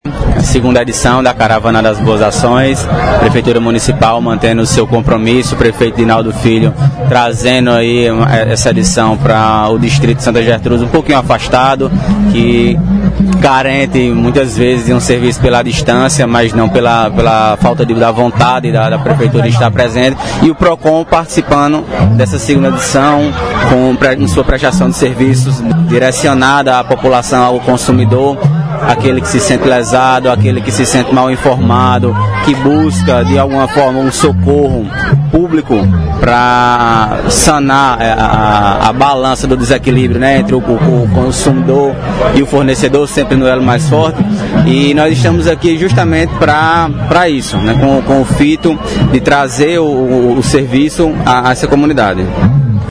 • Fala do secretário do PROCON – Patos, Bruno Maia –